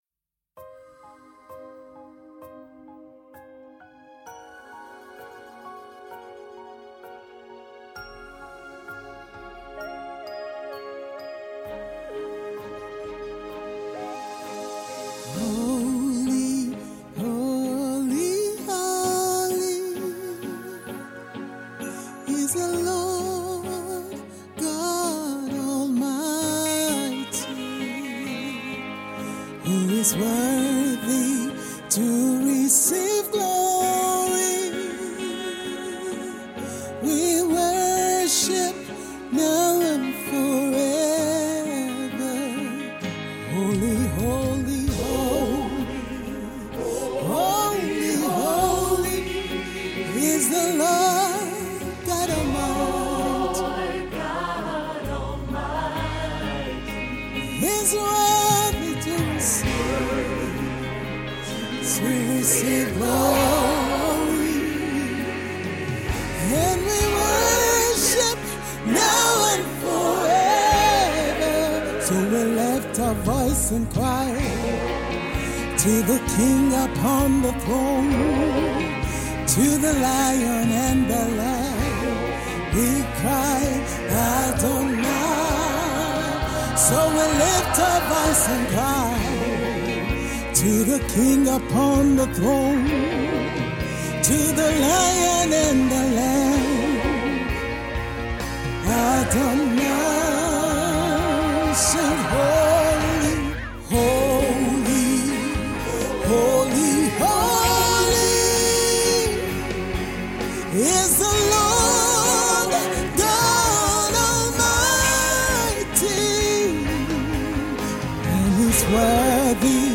Renowned for her deeply spiritual and melodious songs
Live recording project